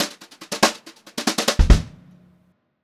144GVFILL1-R.wav